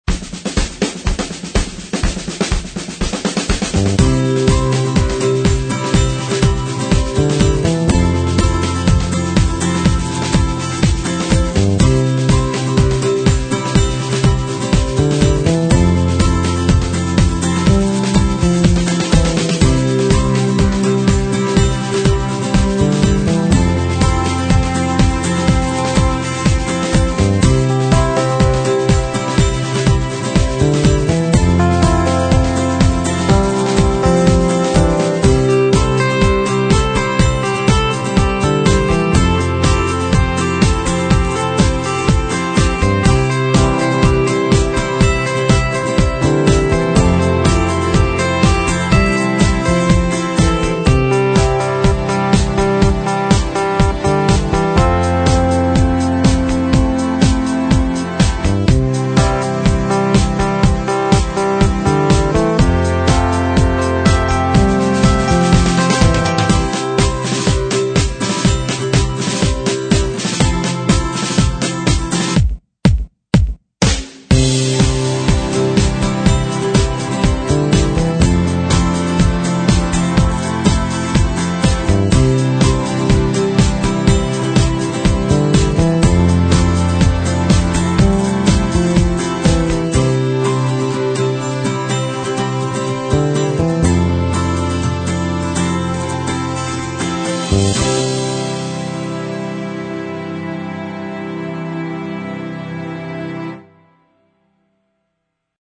描述：爱国主义题材和企业视频强调乐观。 编曲包括原声吉他，电吉他，小军鼓，稳定踢鼓和弦部分。
Sample Rate 采样率16-Bit Stereo 16位立体声, 44.1 kHz